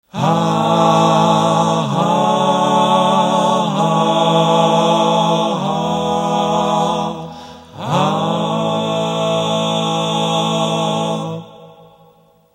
見て笑っていても仕方ないので、カデンツでも一発録音してみる。 さすがにノイズが少なく、音も良い。
うーん、音質は良いが声が…。エフェクターかけまくって誤魔化してあるけど、タイミングのズレはどうしようもない。